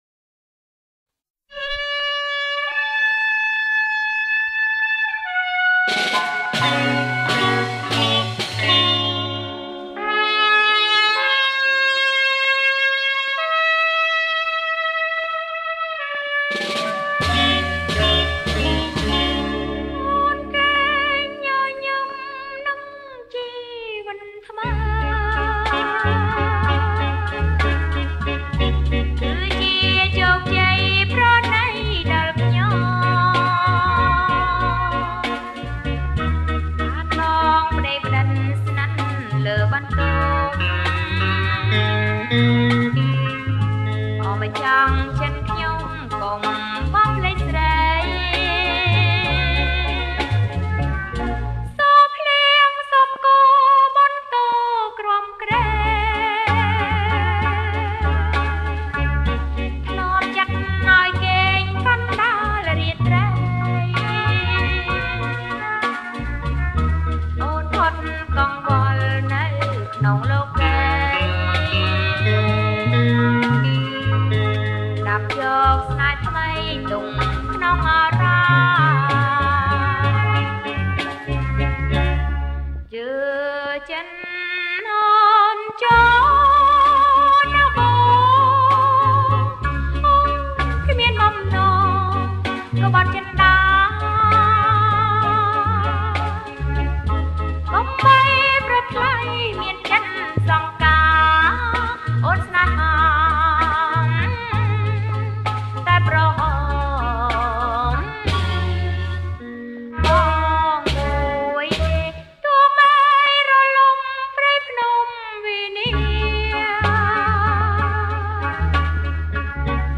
• ប្រគំជាចង្វាក់ SLOW ROK
ប្រគំជាចង្វាក់  SLOW ROCK